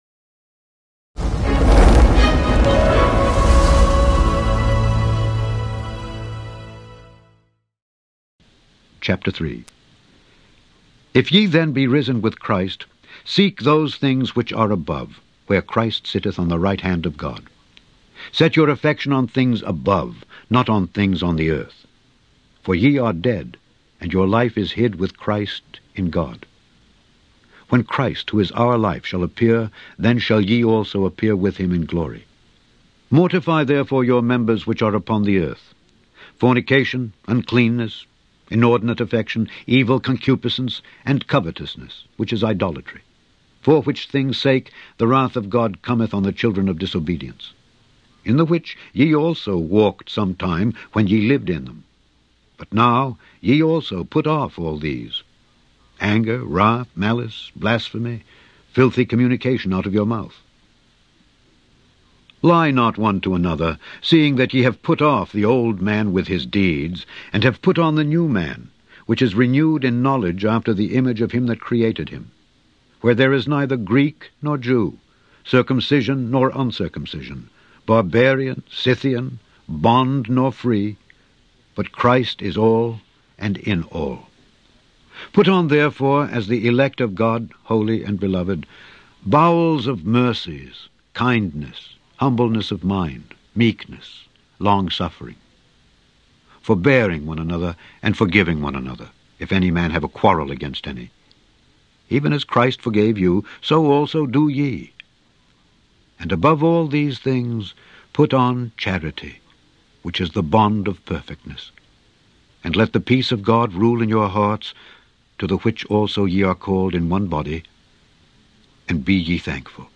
Daily Bible Reading: Colossians 3-4
In this podcast listen to Alexander Scourby read Colossians 3-4.